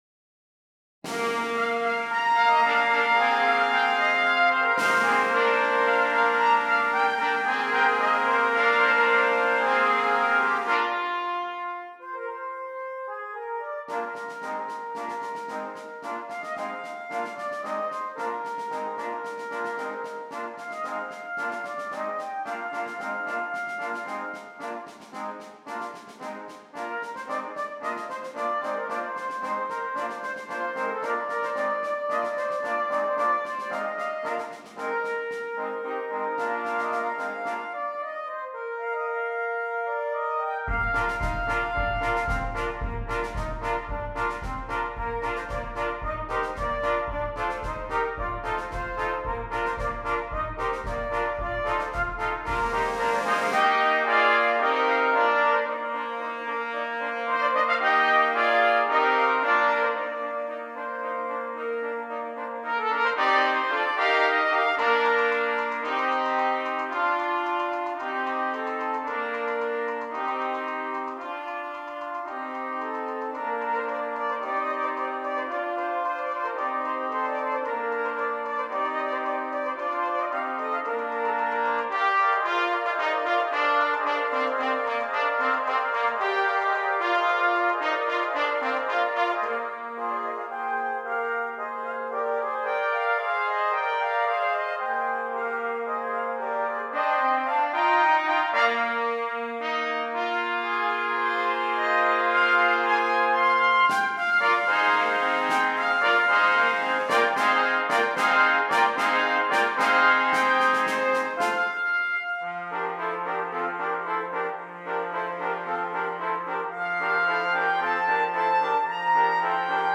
5 Trumpets